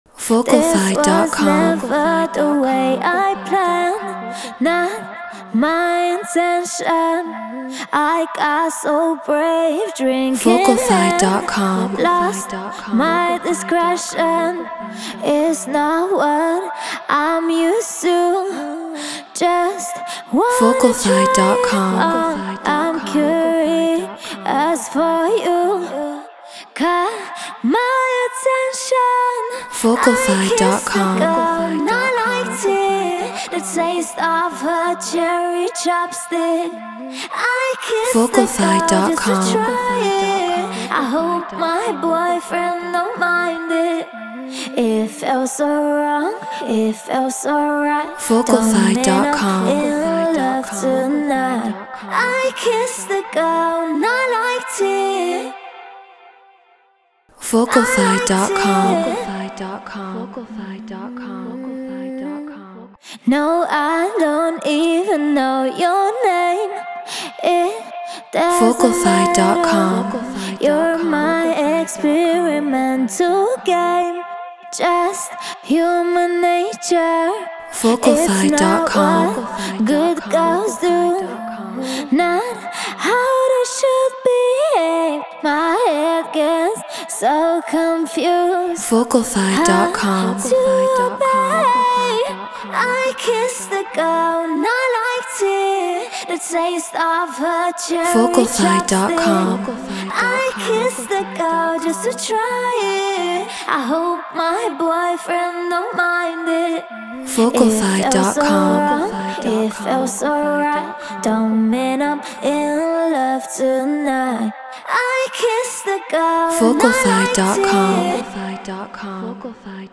Non-Exclusive Vocal.
Our singers and producers covered the original song.